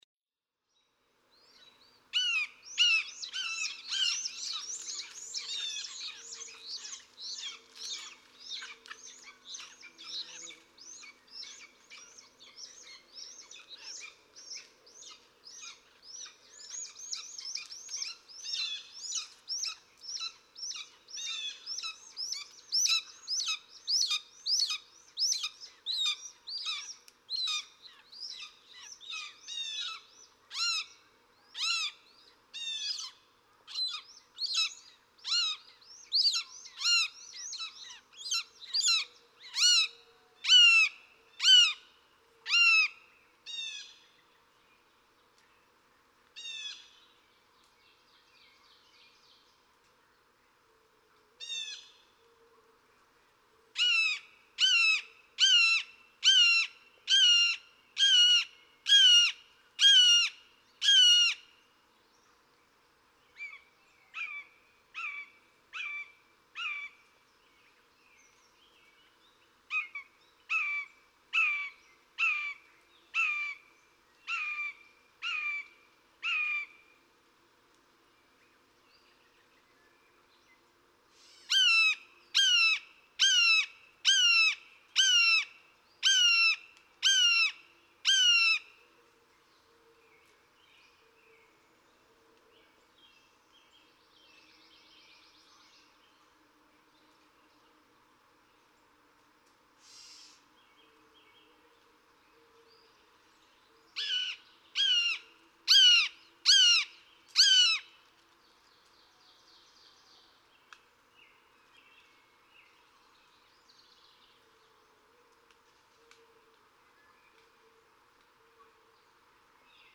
Red-shouldered hawk
♫38. Nestling red-shouldered hawks. The younger birds in the nest already have a hint of the adult kee-aah in their voices (e.g., 0:30-0:43, and 1:03-1:17), and the oldest sibling perched outside the nest is very convincing (e.g., at 0:54 and 1:21).
Belchertown, Massachusetts.
038_Red-shouldered_Hawk.mp3